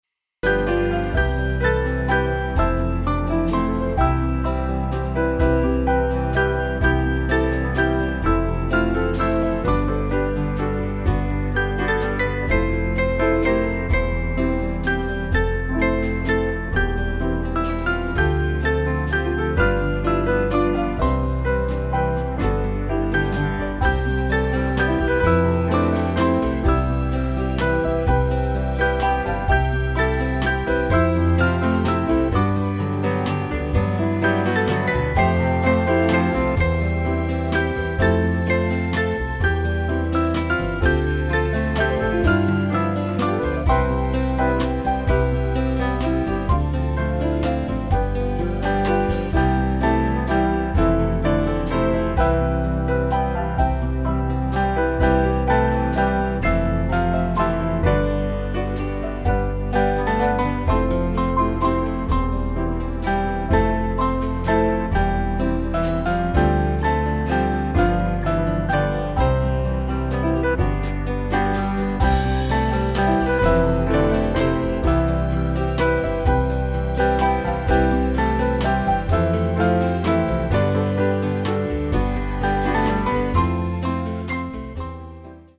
Small Band
Slow Waltz Rhythm